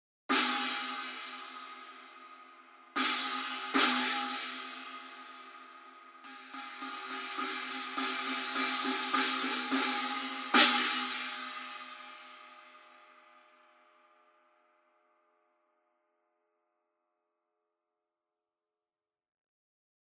Это обеспечивает невероятно широкий динамический диапазон, землистый теплый звук, четкую атаку и отличную читаемость в миксе.
Masterwork 16 Custom Pointer China sample
CustomPointer-China-16.mp3